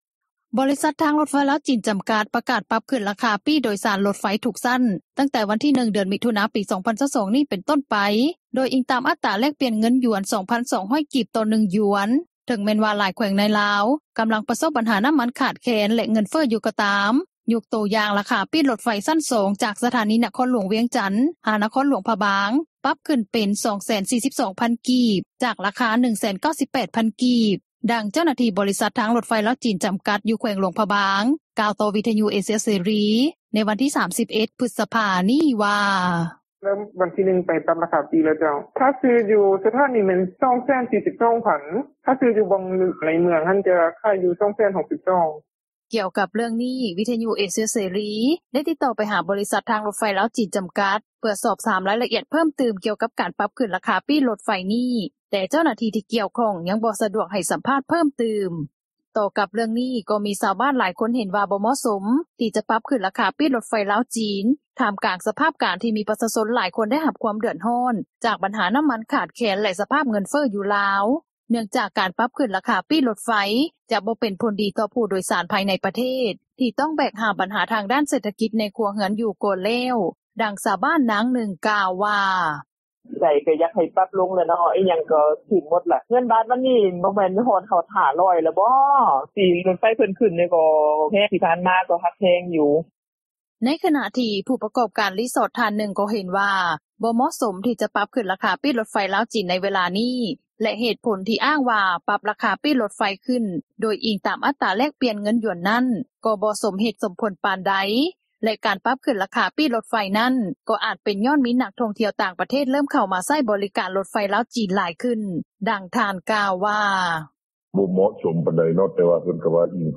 ດັ່ງຊາວບ້ານນາງນຶ່ງ ກ່າວວ່າ:
ດັ່ງຊາວລາວນາງນຶ່ງ ກ່າວວ່າ: